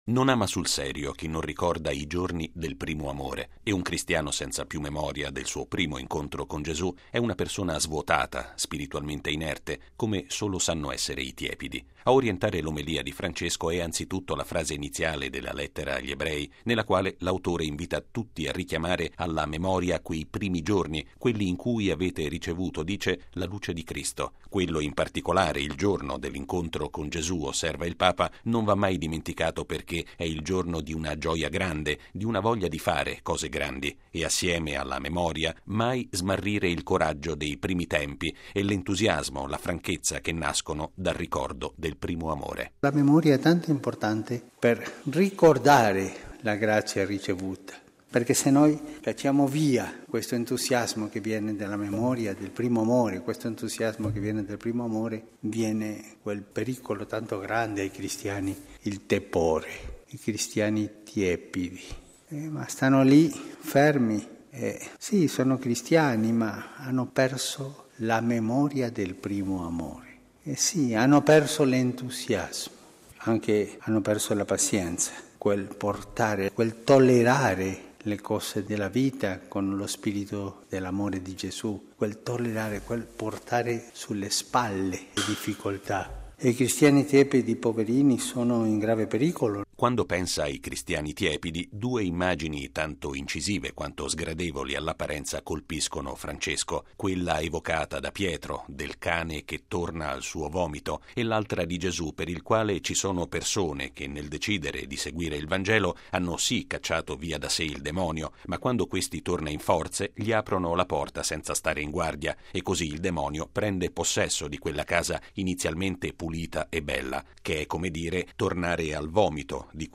Un cristiano deve sempre custodire in sé la “memoria” del suo primo incontro con Cristo e la “speranza” in Lui, che lo spinge ad andare avanti nella vita con il “coraggio” della fede. Lo ha affermato Papa Francesco all’omelia della Messa del mattino, presieduta nella cappella di Casa Santa Marta.